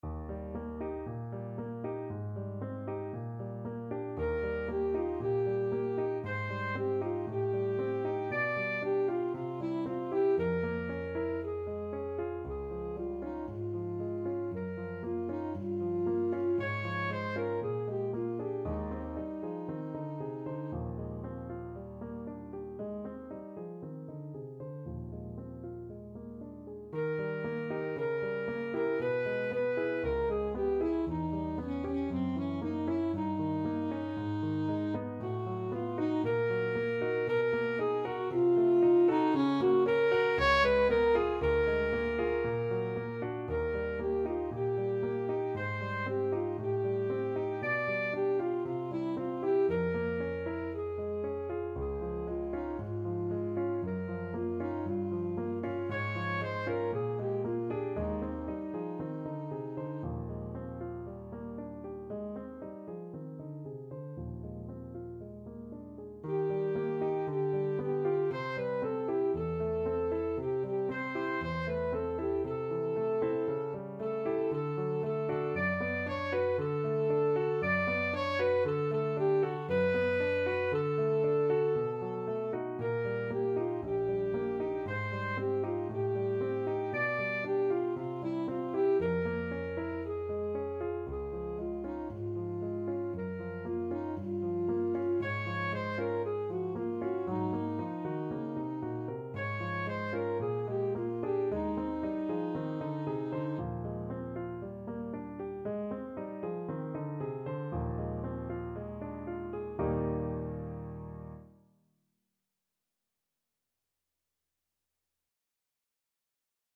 Alto Saxophone
4/4 (View more 4/4 Music)
Allegro moderato =116 (View more music marked Allegro)
Classical (View more Classical Saxophone Music)